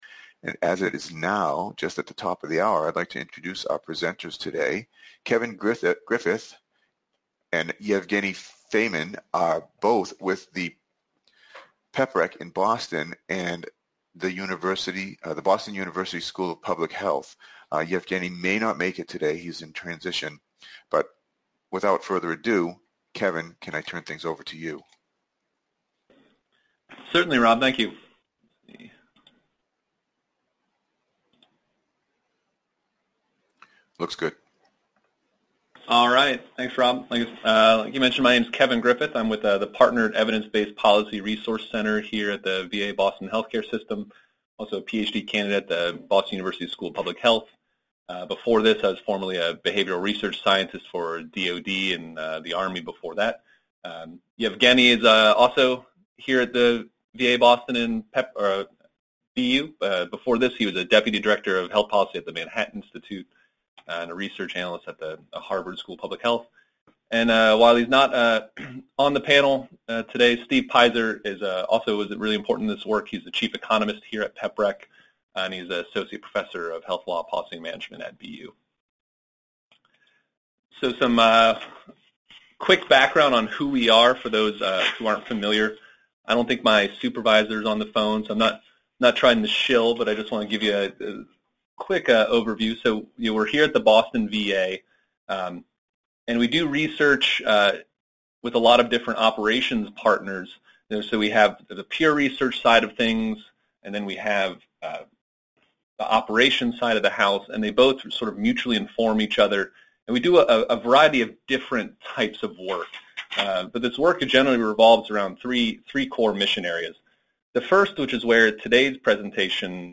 MPA Seminar date